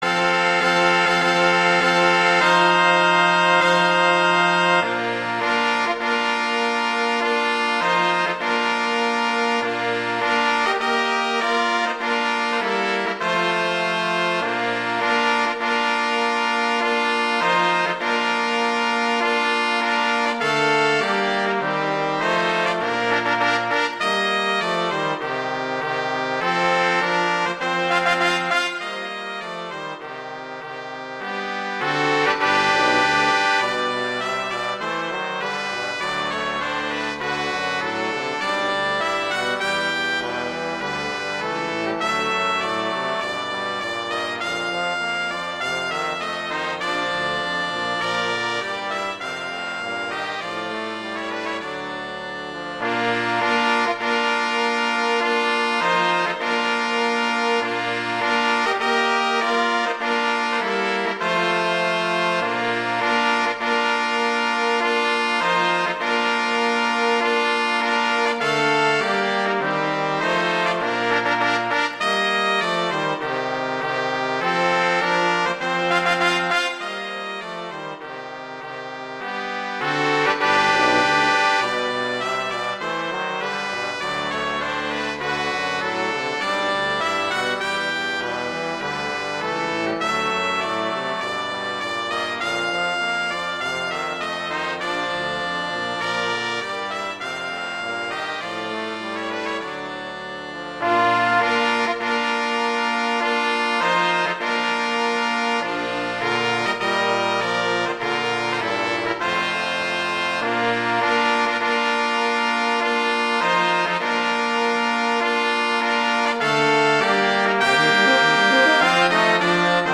arrangements for brass quartet
trumpet I:
trombone:
tuba: